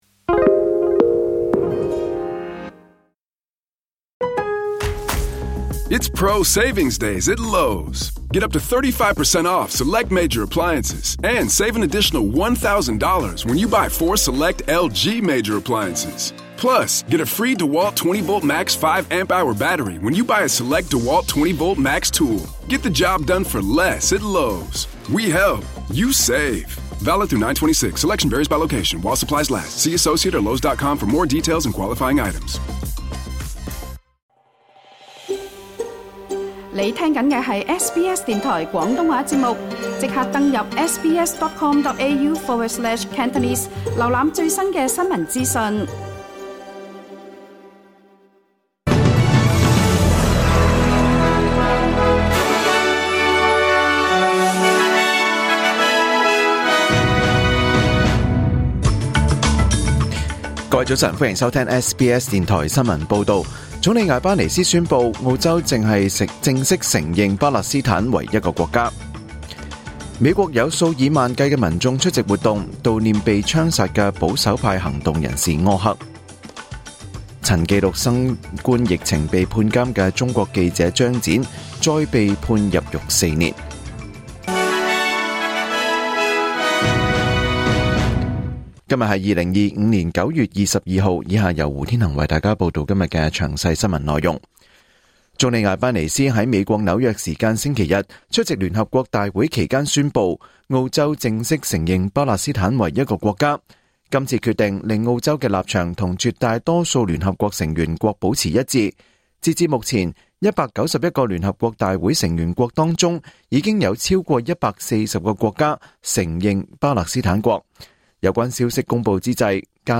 2025年9月22日SBS廣東話節目九點半新聞報道。